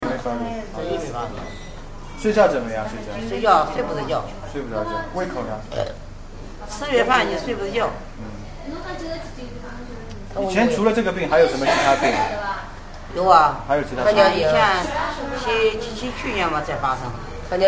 语声低微.mp3